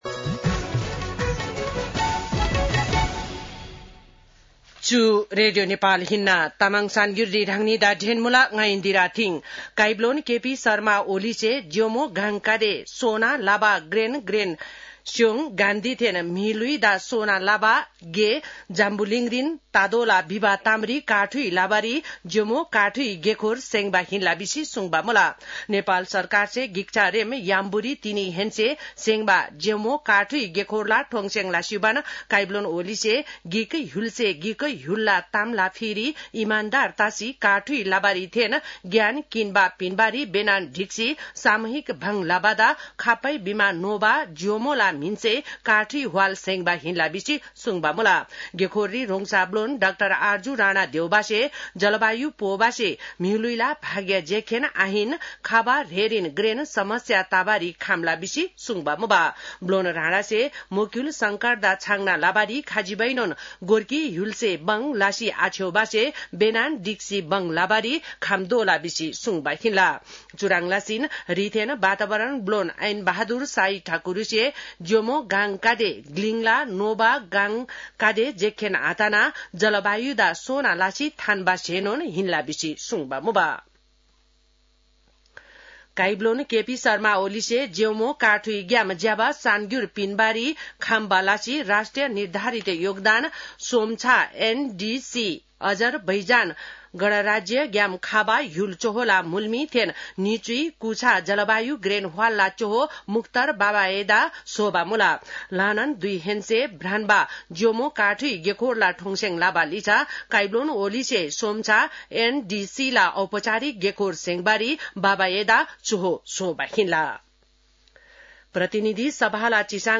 तामाङ भाषाको समाचार : २ जेठ , २०८२
Tamang-news-02-2.mp3